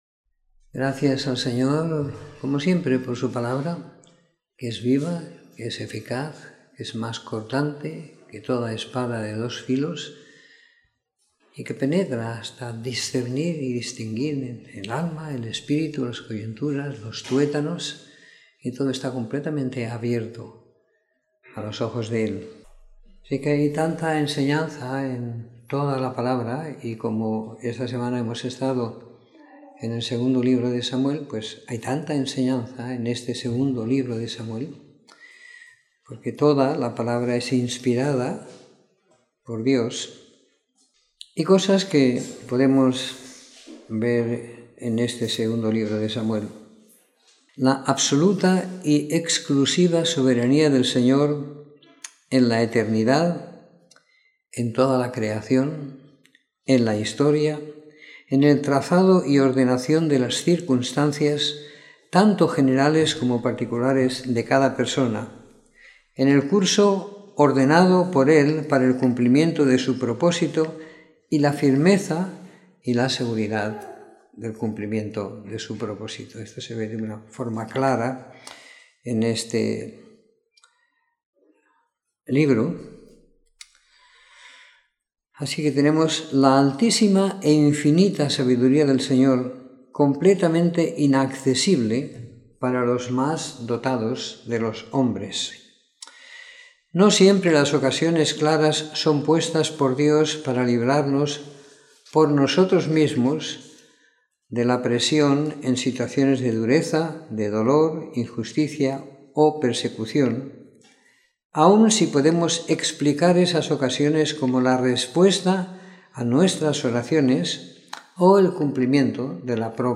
Comentario en el libro de 2º Samuel siguiendo la lectura programada para cada semana del año que tenemos en la congregación en Sant Pere de Ribes.